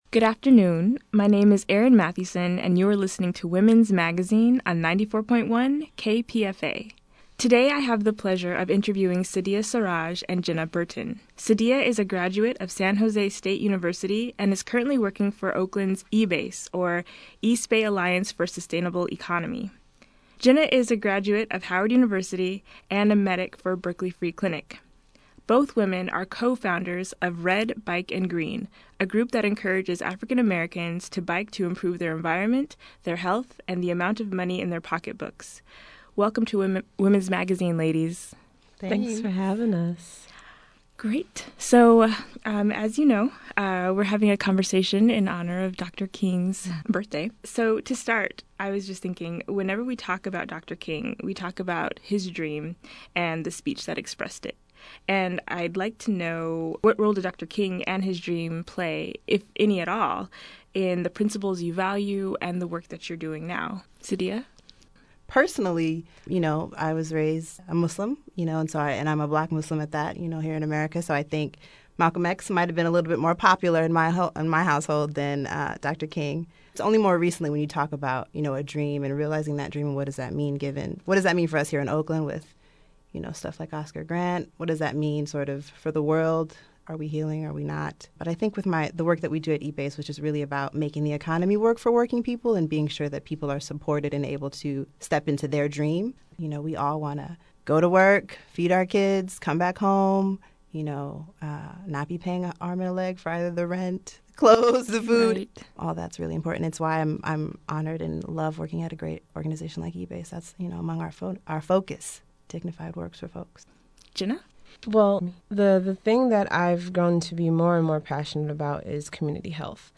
Young African American Women Activists Discuss Dr. King and the Obamas